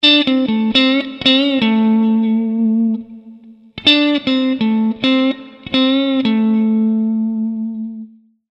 Fraseggio blues 02
Alla terzina iniziale viene fatta seguire una coppia di note C ad ottavi (terzinati), che vengono anticipati da una acciaccatura di un semitono.